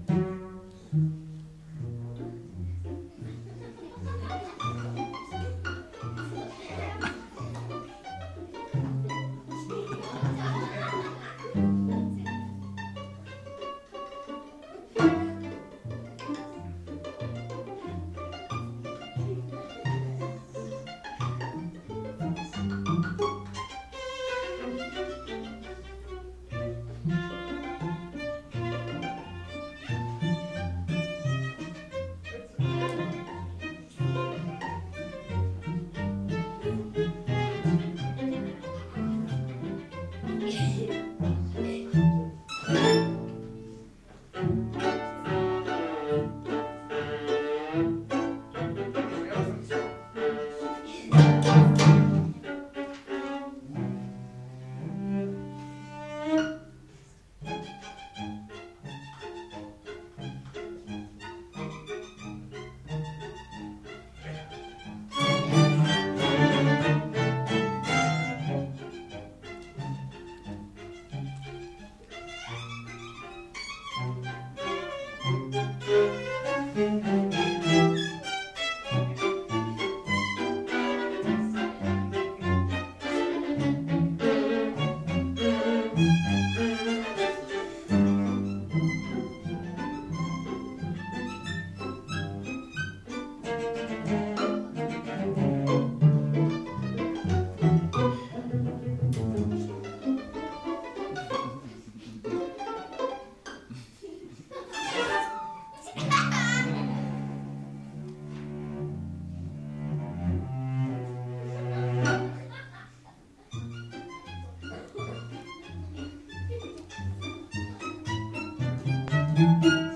Quartet does a polka